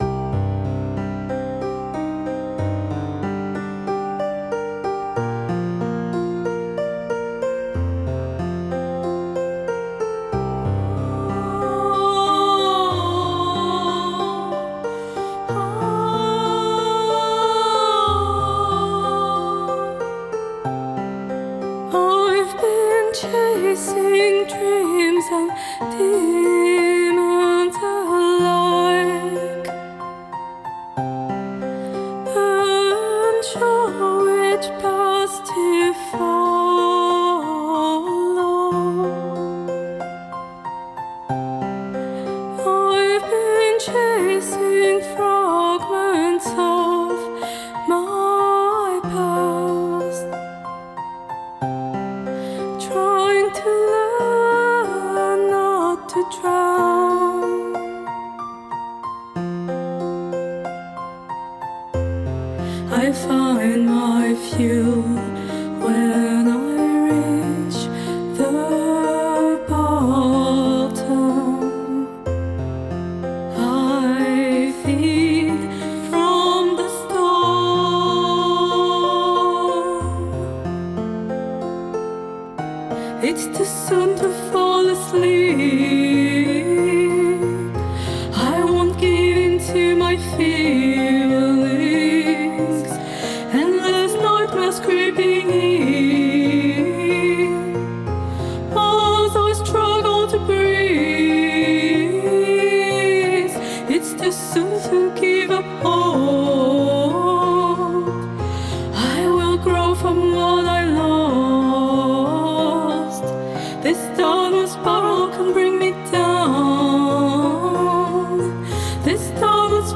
acoustic song